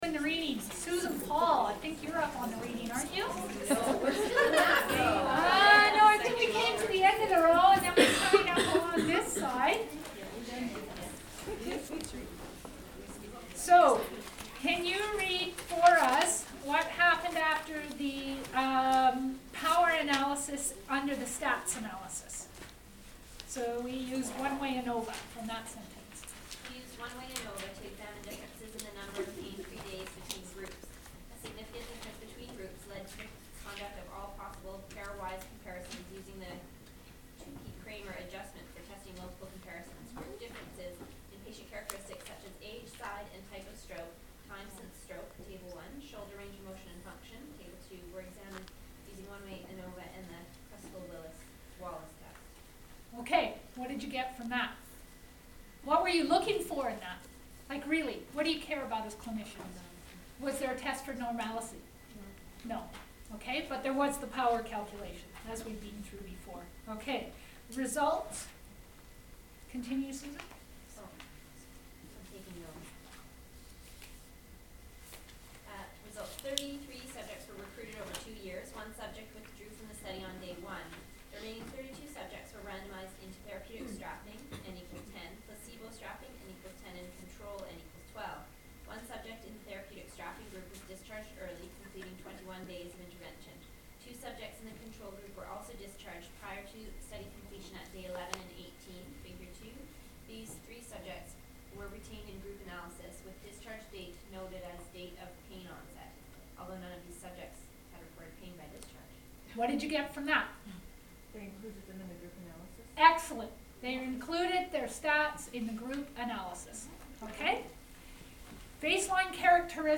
EBP STEP 2 - APPRAISING THE EVIDENCE - How do I know the article is any good? Step 2: How to appraise the literature (Quantitative articles – practical session).